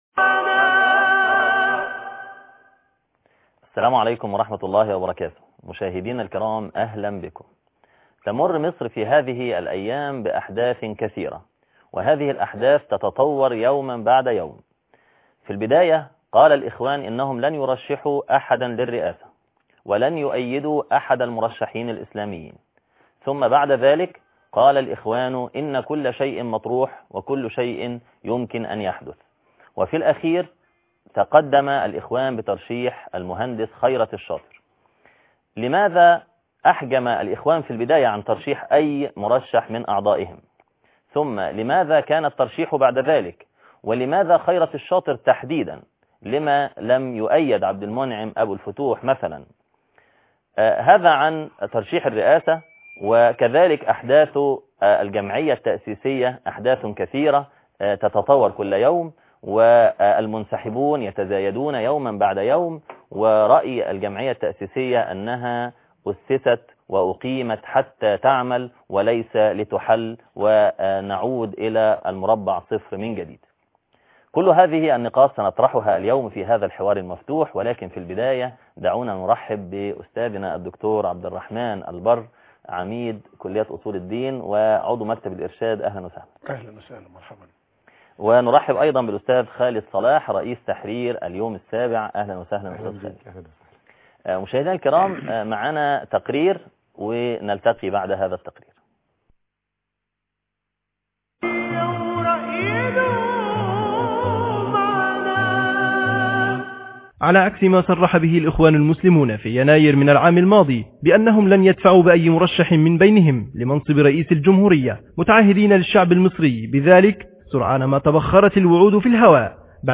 حوار مفتوح